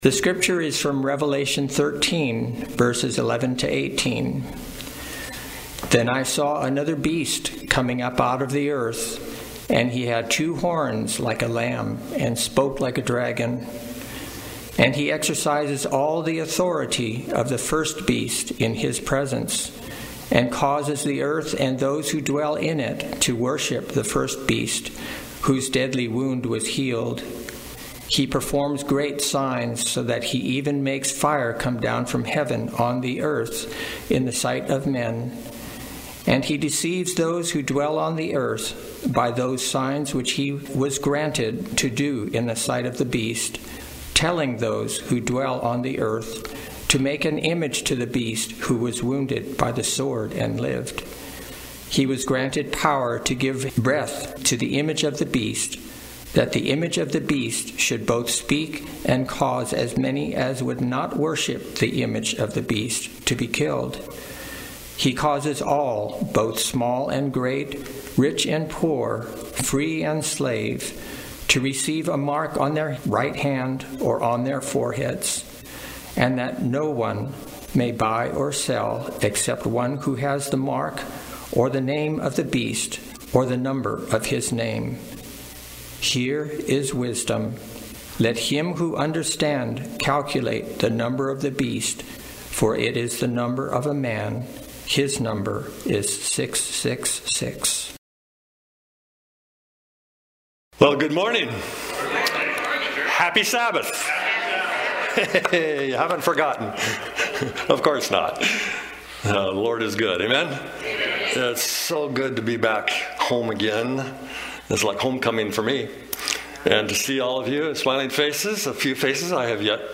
Seventh-day Adventist Church, Sutherlin Oregon
Sermons and Talks 2024